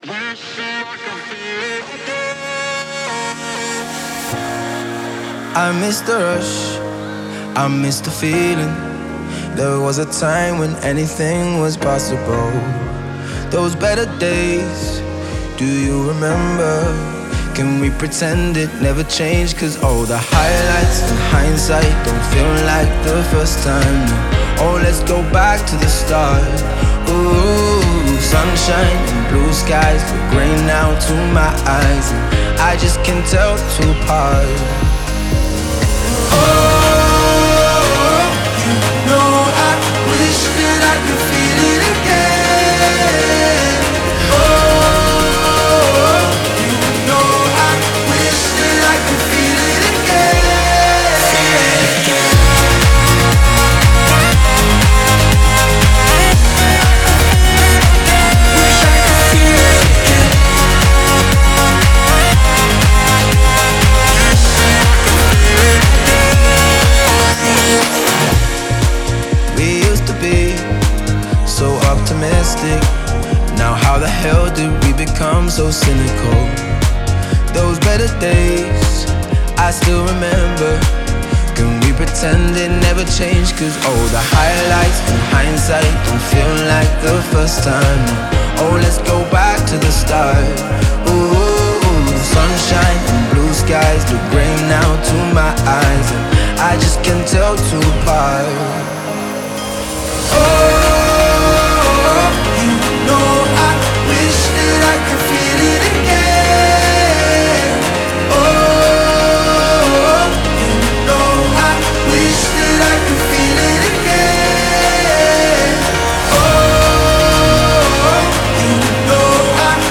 это энергичная поп-песня в жанре EDM